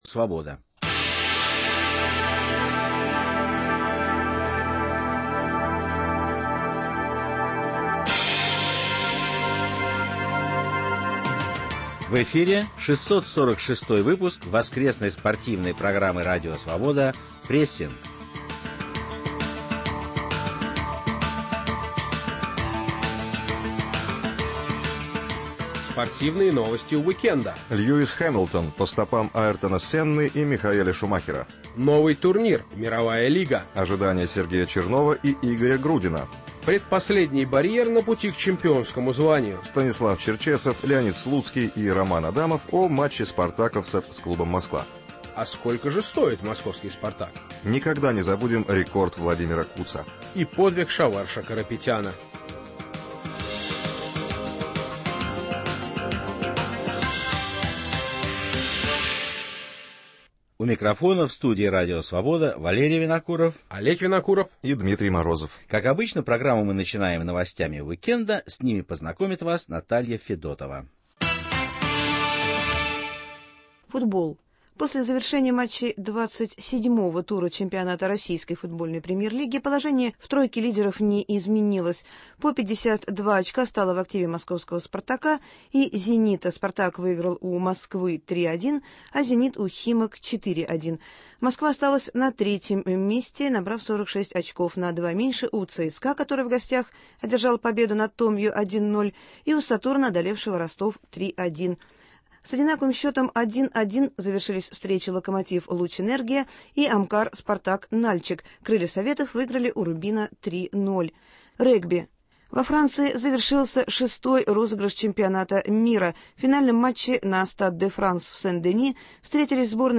В спортивной программе "Прессинг" - не только свежая информация, анализ и размышления, но и голоса спортсменов и тренеров всего мира с откровениями о жизни, о партнерах и соперниках. Речь не только о самом спорте, ибо он неотделим от социальных, экономических, нравственных и национальных проблем.